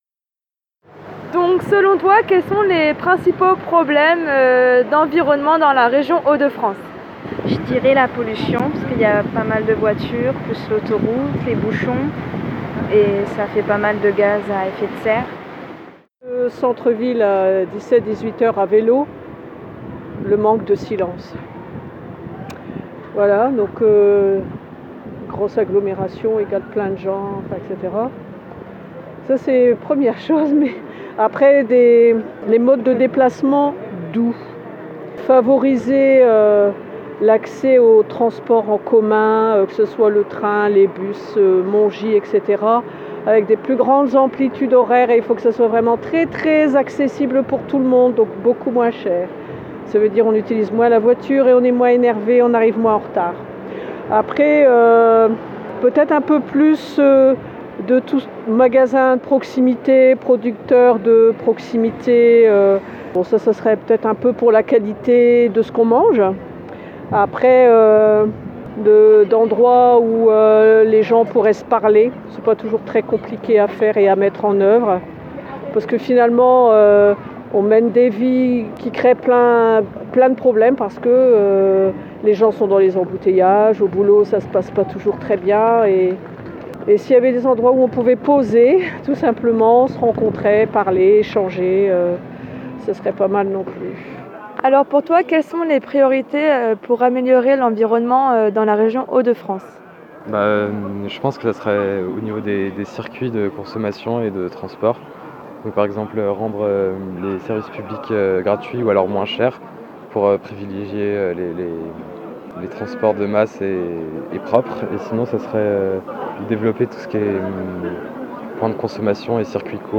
réalisé à Lille le 22/11/2016